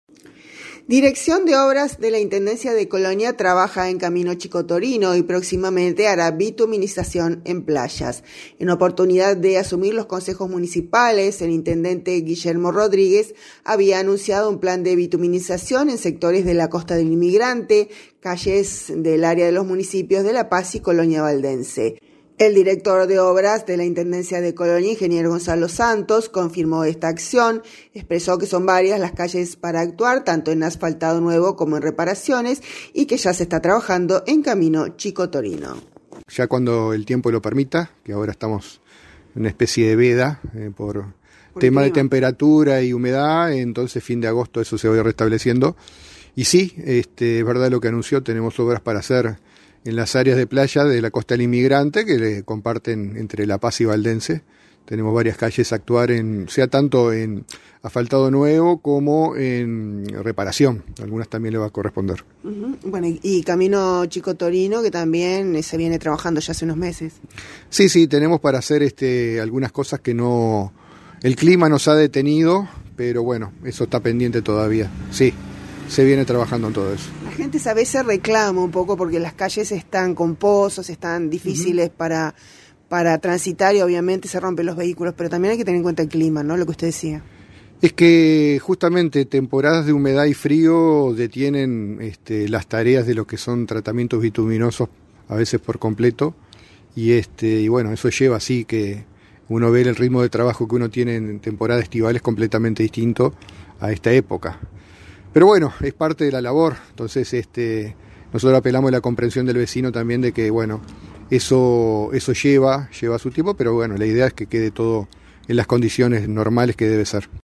Consultado sobre este tema el Director de Obras de la Intendencia de Colonia Ing. Gonzalo Santos, en el marco de su visita a la zona Este, expreso que son varias las calles para actuar, tanto en asfaltado nuevo como en reparaciones.